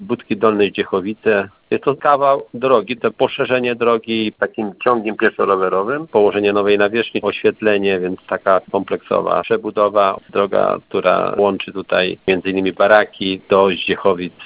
Inwestycja ta została zrealizowana dzięki dofinansowaniu z Funduszu Dróg Samorządowych. Mówi starosta stalowowolski Janusz Zarzeczny: